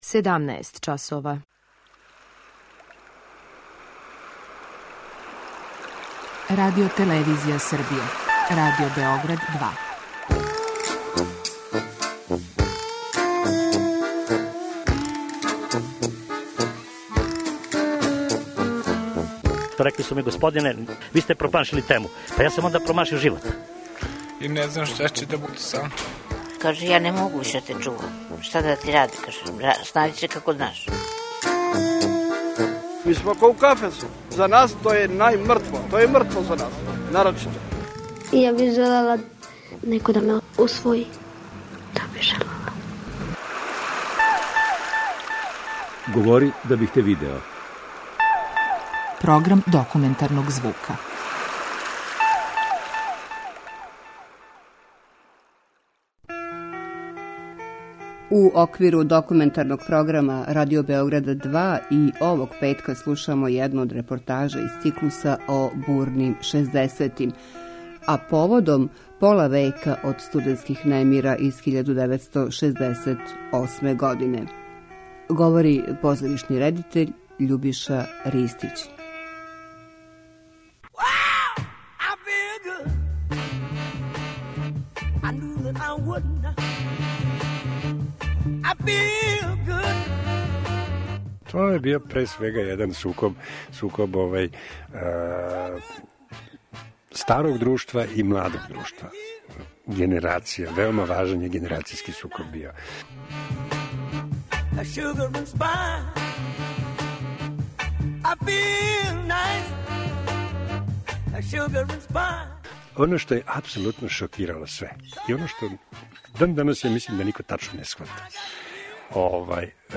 Документарни програм: 1968. - педест година после
Овог петка говори Љубиша Ристић, позоришни редитељ.